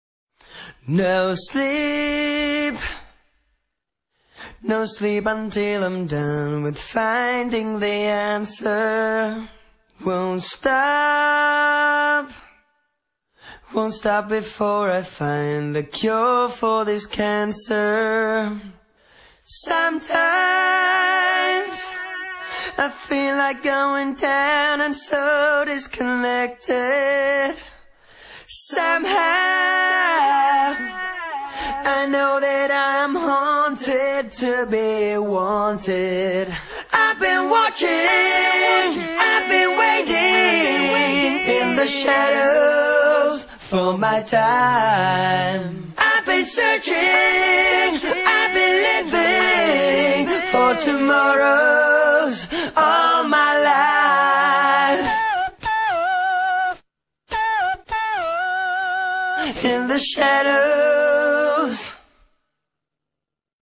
Главная » Файлы » Акапеллы » Скачать Зарубежные акапеллы